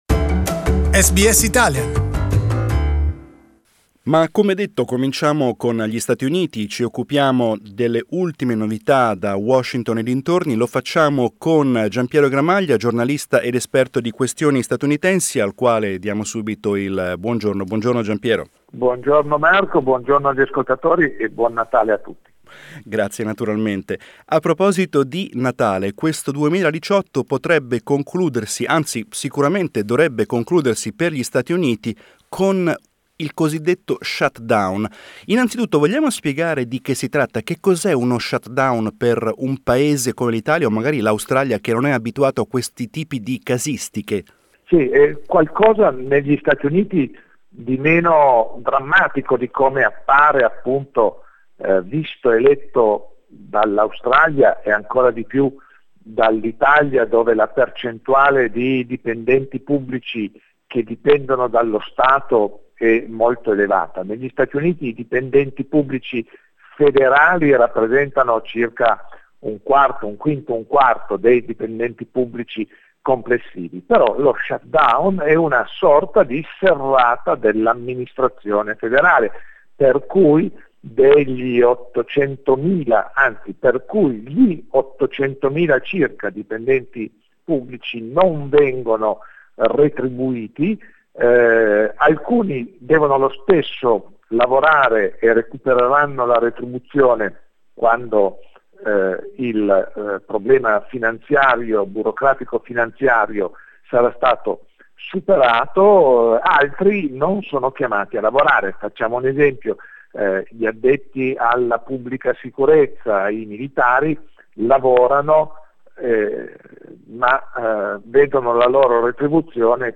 We analyse the latest developments in the US with former ANSA editor